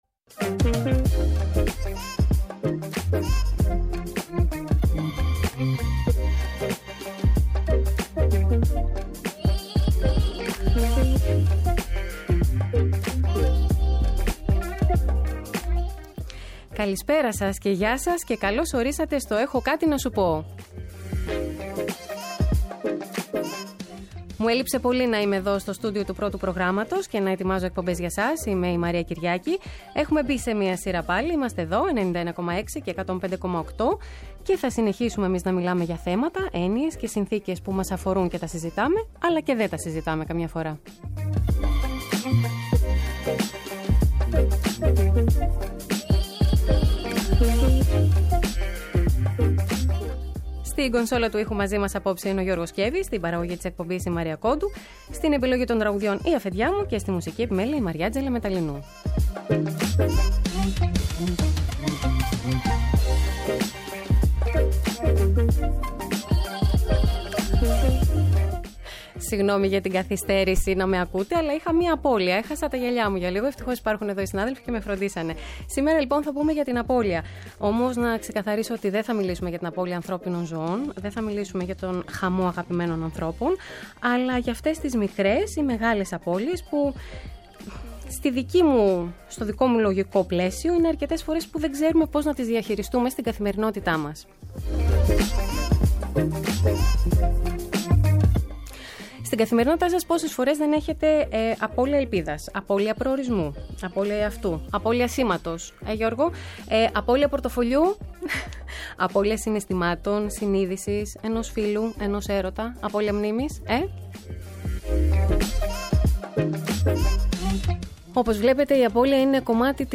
Κάθε Παρασκευή 7 με 8 το βράδυ, ένα τραγούδι γίνεται οδηγός για το κεντρικό θέμα σε κάθε εκπομπή. Για το ευ στο ζην, από συναισθήματα και εμπειρίες μέχρι πεποιθήσεις που μας κάνουν να δυσλειτουργούμε ή να κινητοποιούμαστε, έχουν κάτι να μας πουν ειδικοί σε επικοινωνία με ακροατές.